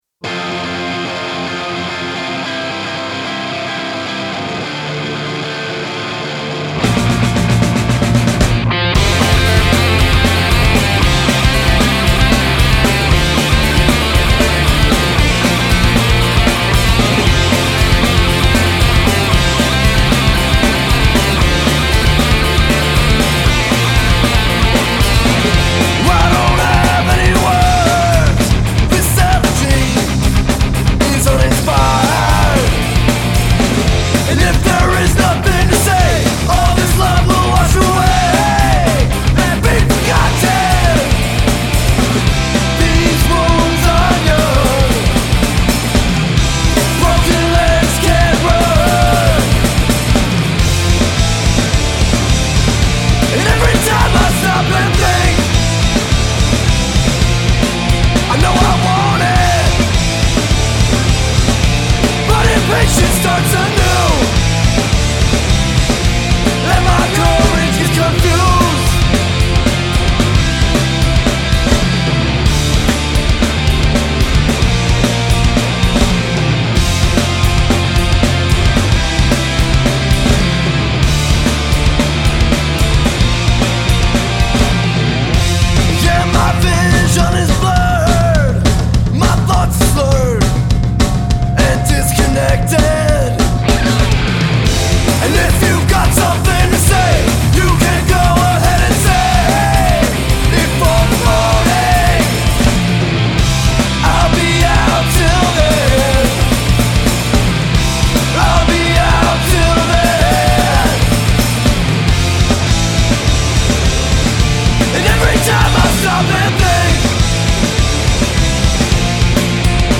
melodic punk band